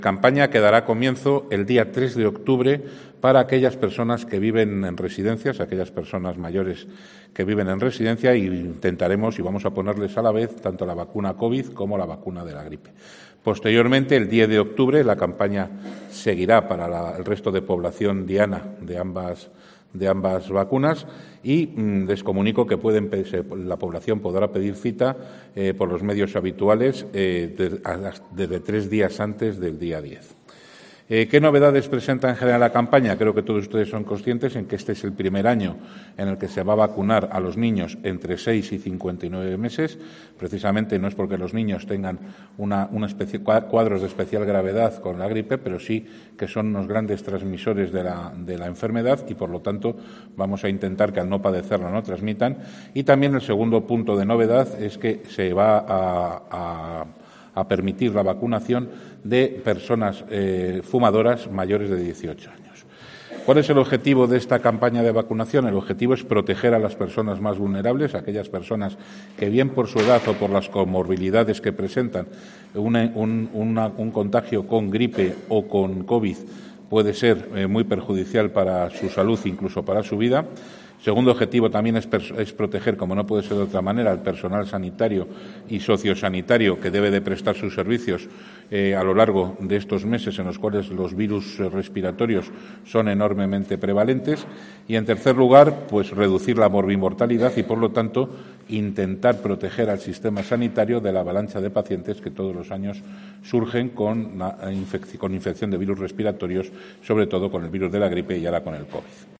Alejandro Vázquez, consejero de Sanidad en Castilla y León, informa de la vacunación contra la gripe